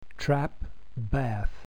American English
BATH
trapbathGA.mp3